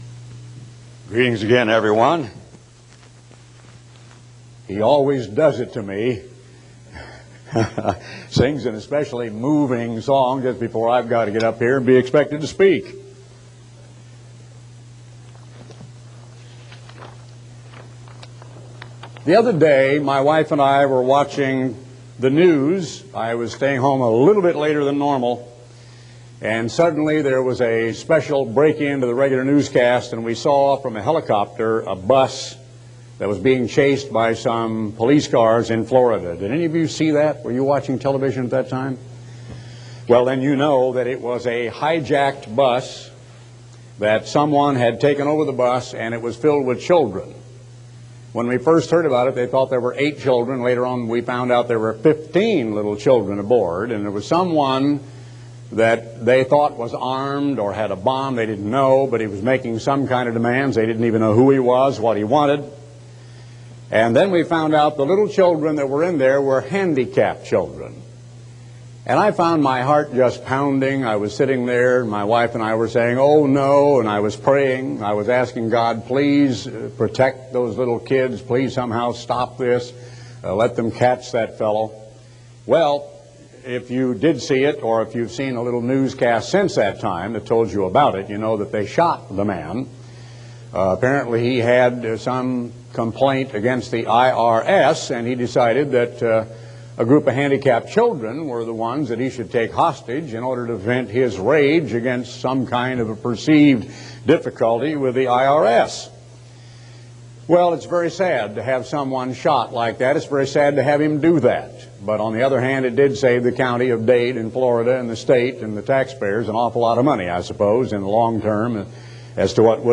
Message from Garner Ted Armstrong on November 4, 1994
Sermons given by Garner Ted Armstrong in audio format.